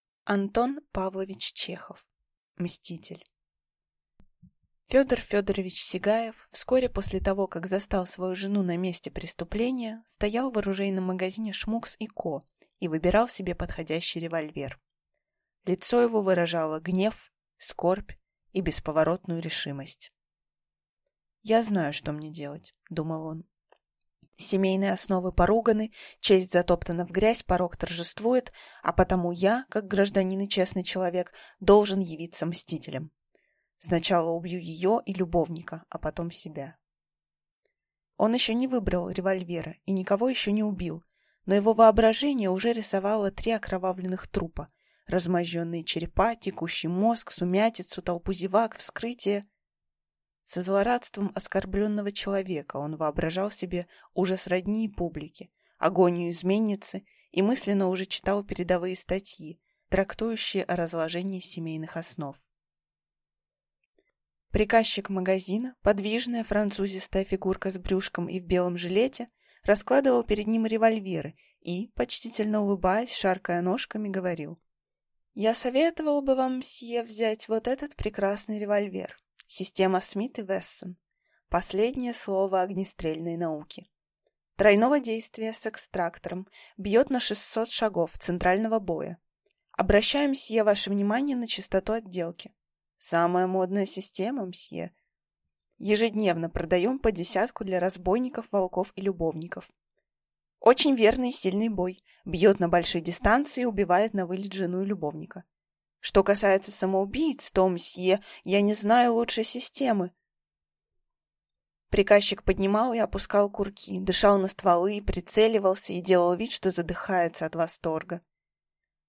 Aудиокнига Мститель